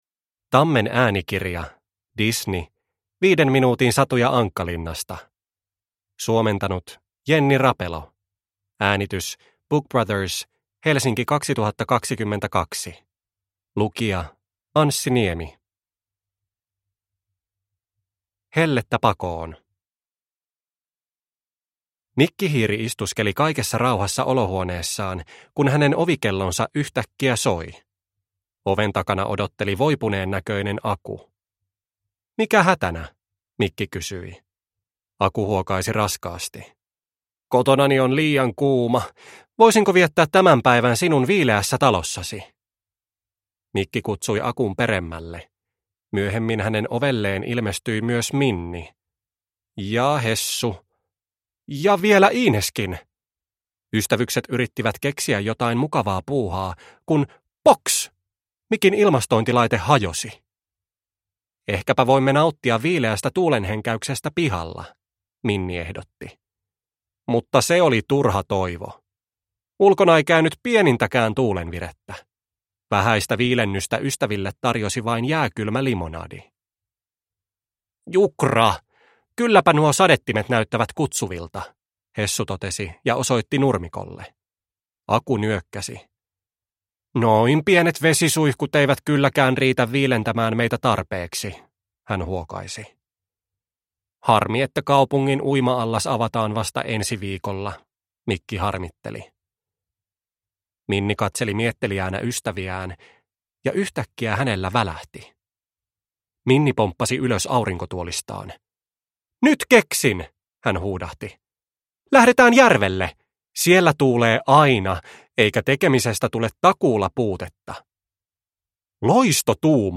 Disney. 5 minuutin satuja Ankkalinnasta – Ljudbok – Laddas ner